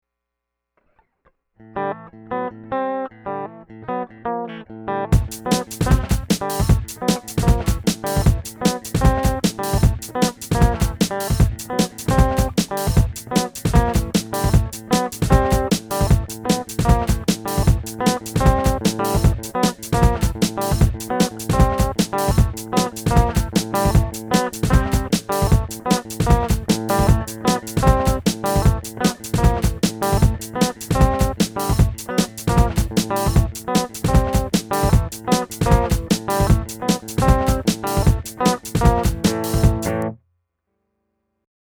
"Turning Time Around" is my term for when you think you are feeling the beat of a song, then something enters to define the beat, and it's not where you thought it was.